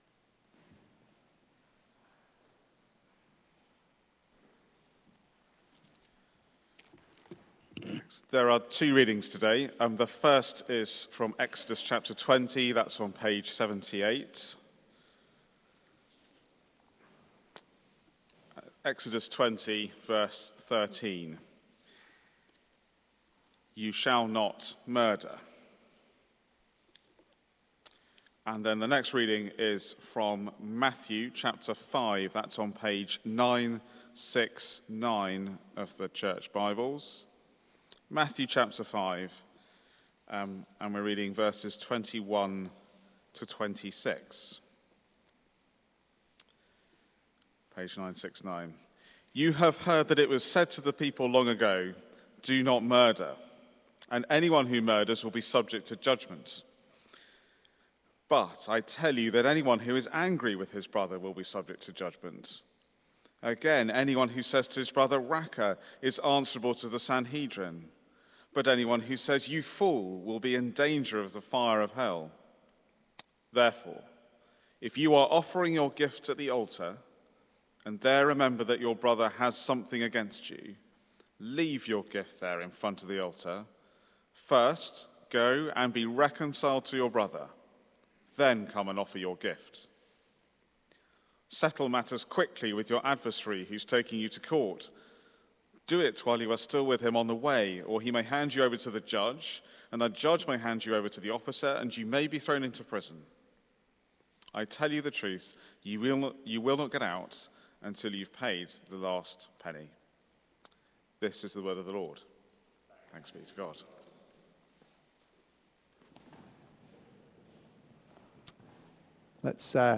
Series: The Ten Commandments Theme: The Value of Life: Love over Anger Sermon